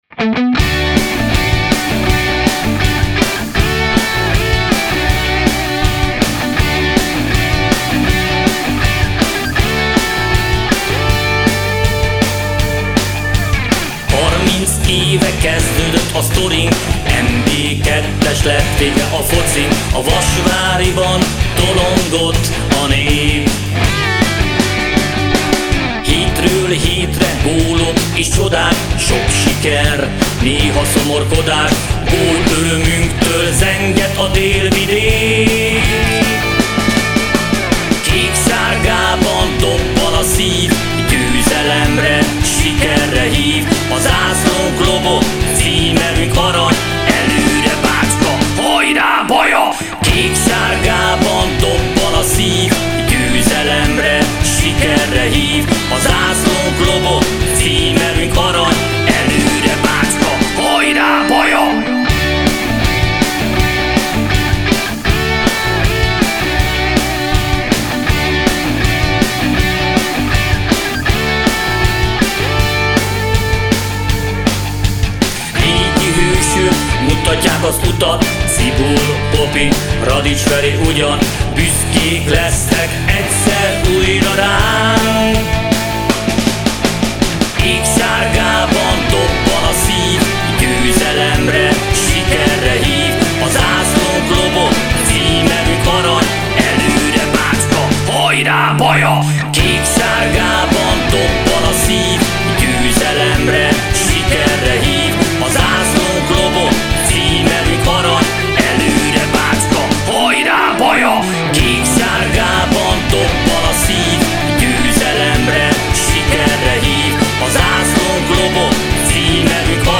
Íme, a bajai futballklub indulója: